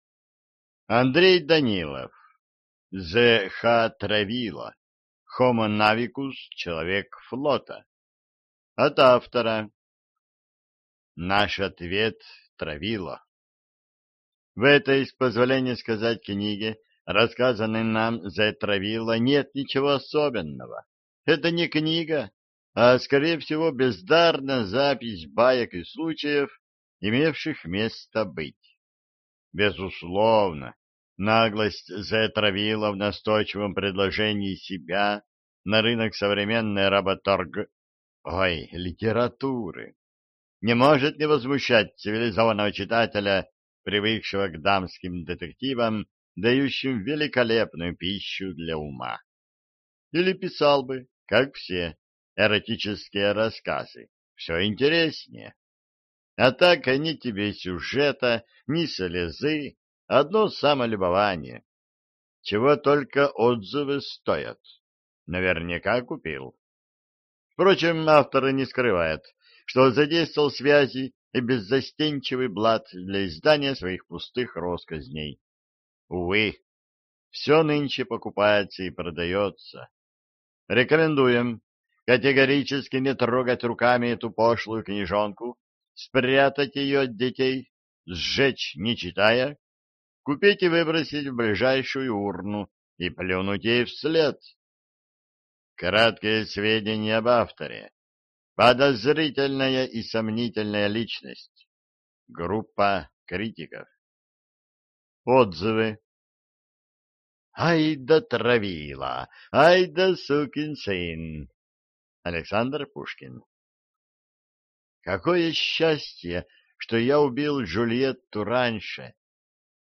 Аудиокнига HOMO Navicus, человек флота. Часть первая | Библиотека аудиокниг